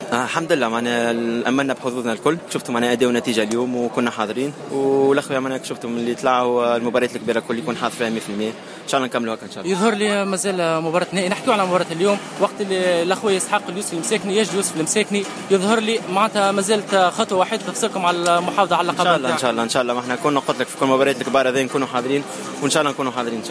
يوسف المساكني : لاعب لخويا القطري